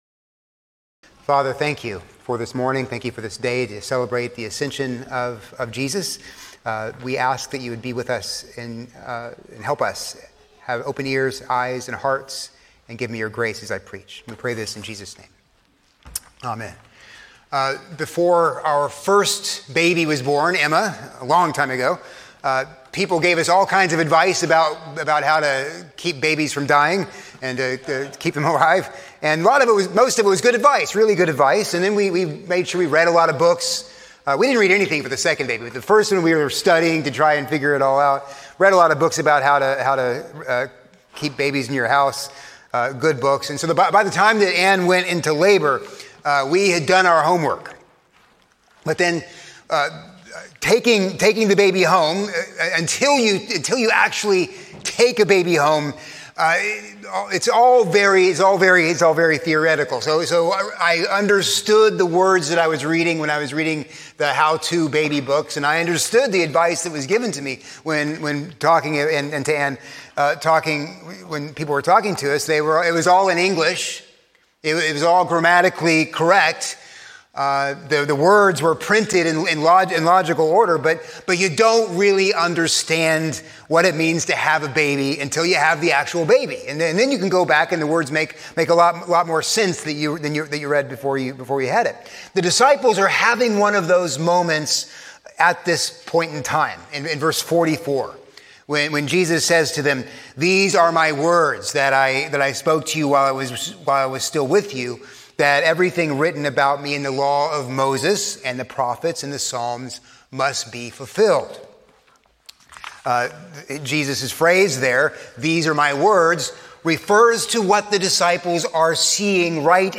A sermon on Luke 24:44-53